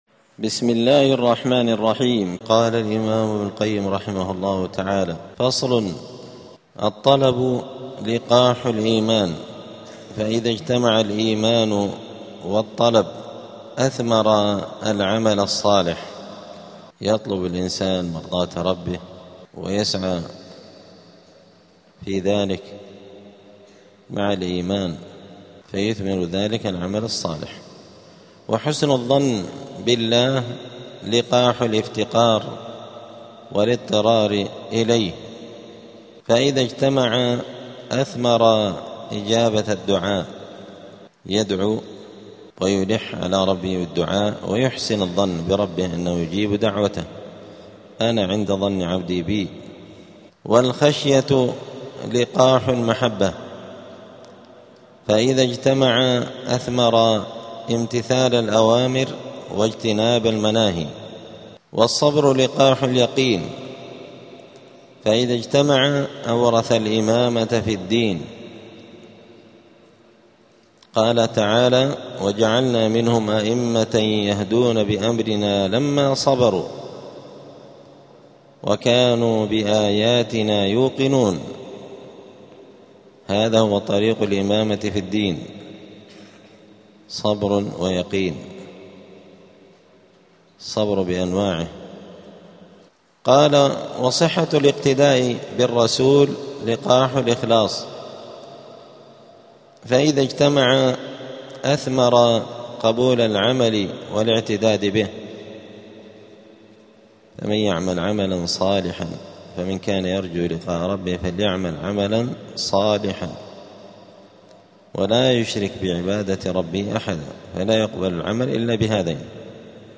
*الدرس الرابع عشر بعد المائة (114) {فصل الطلب لقاح الإيمان}.*